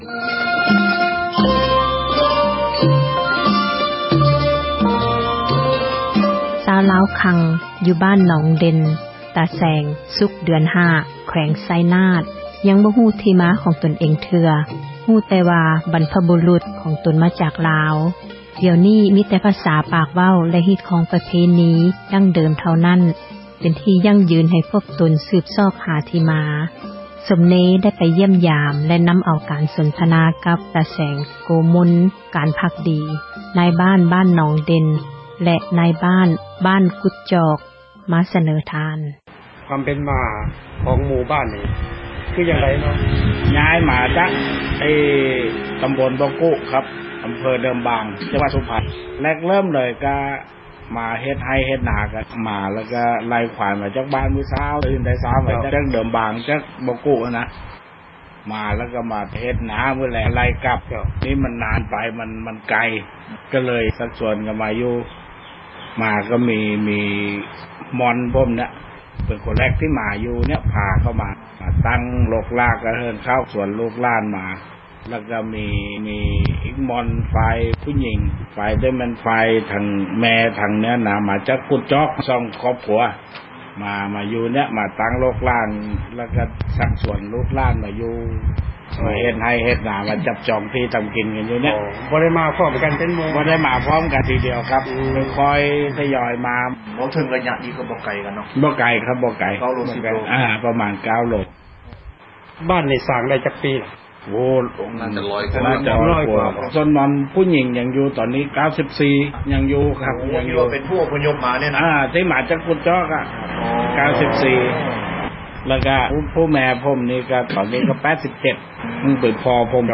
ການ ສົນທະນາ